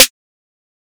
MZ Snare [Houston Drill Hi].wav